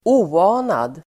Ladda ner uttalet
oanad adjektiv, unforeseen Uttal: [²'o:a:nad] Böjningar: oanat, oanade Synonymer: oväntad, överraskande Definition: som man inte trodde var möjlig Exempel: oanade konsekvenser (unforeseen consequences)